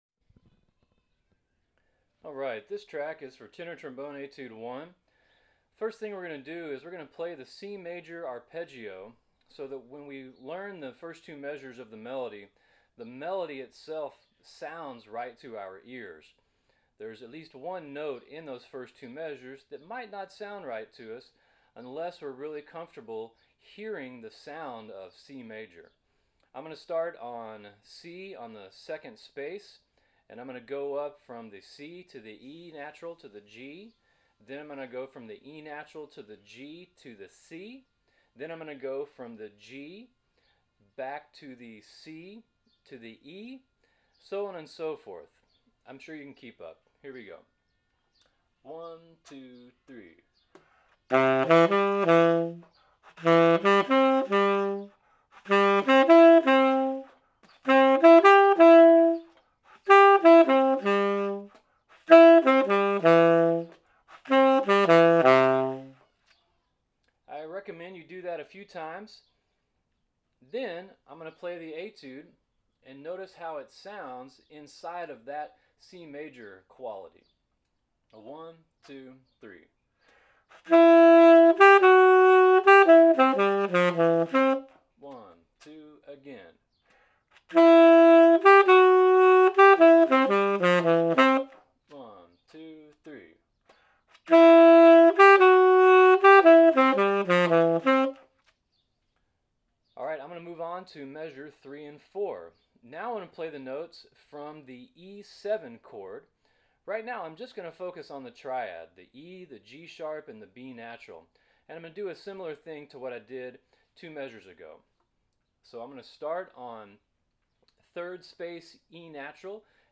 tenor trombone – etudes – 2018-2019
this audio practice-with-me track covers all of 2019 TMEA All-State Jazz Tenor Trombone Etude 1, bpm=160. this is the State Cut.